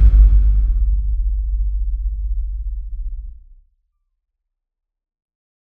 Low End 13.wav